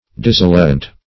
Dissilient \Dis*sil"i*ent\, a. [L. dissiliens, -entis, p. pr. of